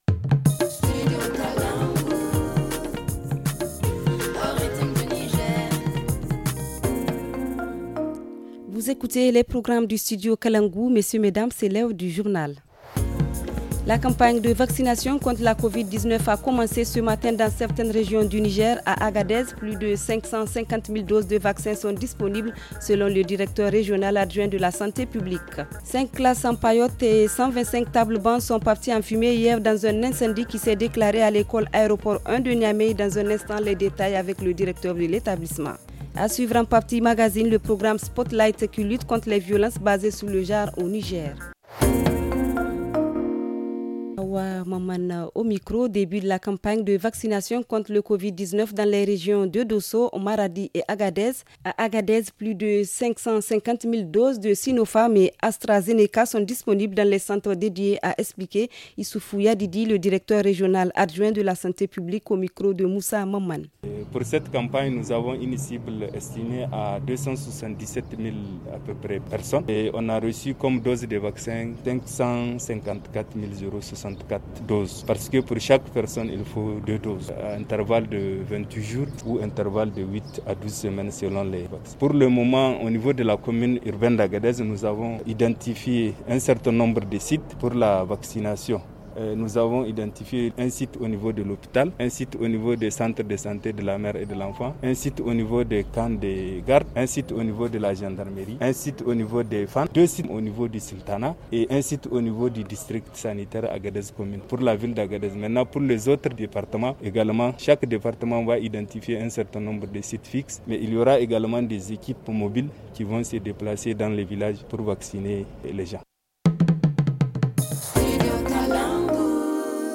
Le journal du 04 mai 2021 - Studio Kalangou - Au rythme du Niger